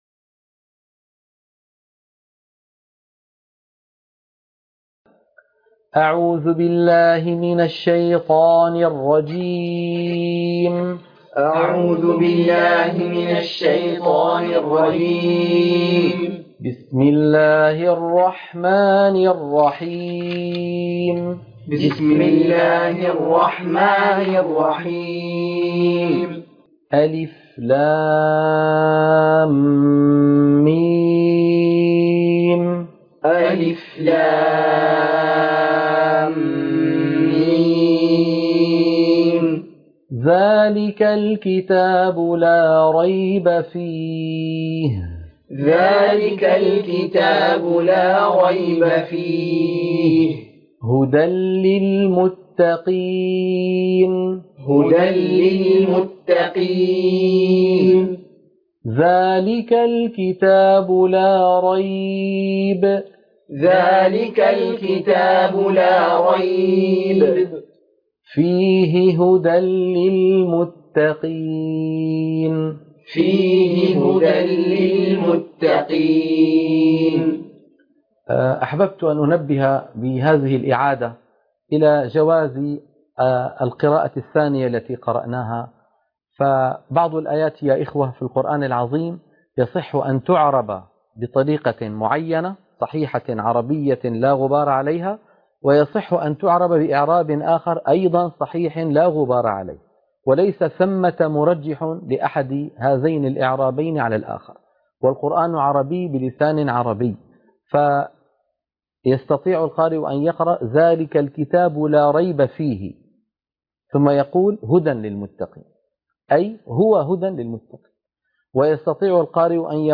تلقين سورة البقرة - الصفحة 2 _ التلاوة المنهجية - الشيخ أيمن سويد